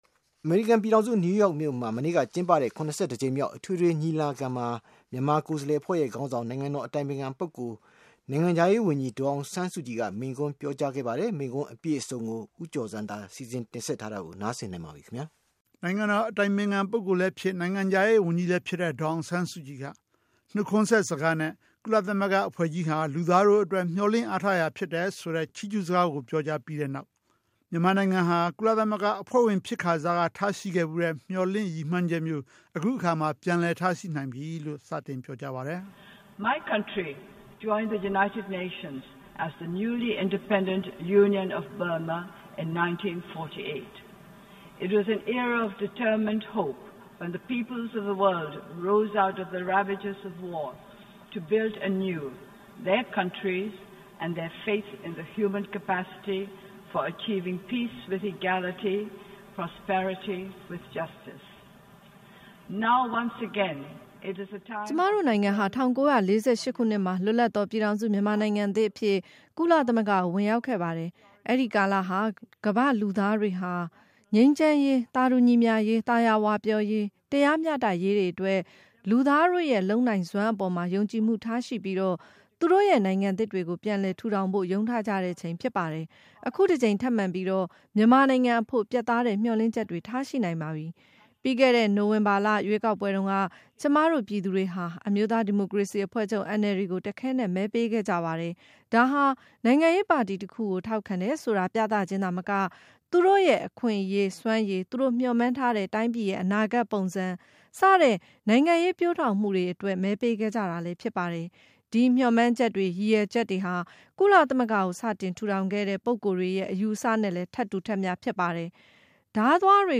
ကုလသမဂ္ဂ အထွေထွေညီလာခံမှာ ပြောကြားတဲ့ ဒေါ်စု မိန့်ခွန်း အပြည့်အစုံ